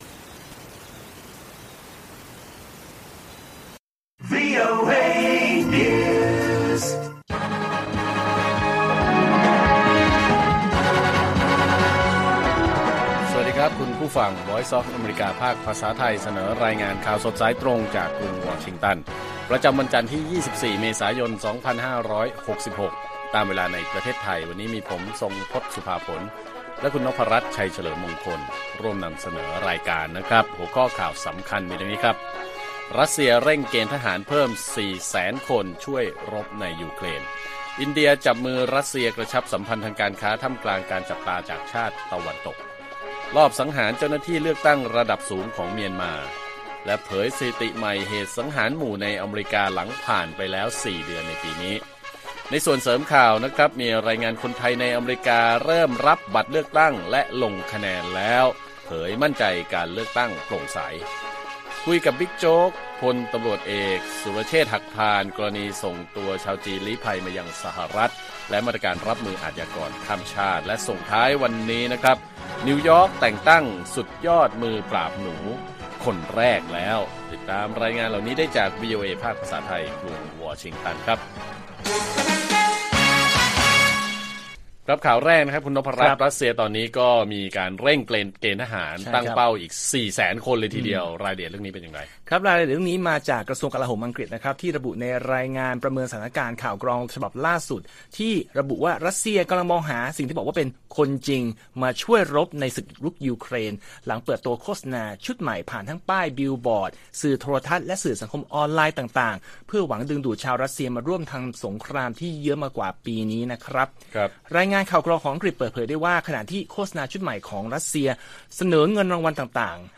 ข่าวสดสายตรงจากวีโอเอไทย 6:30 – 7:00 น. วันที่ 24 เม.ย. 2566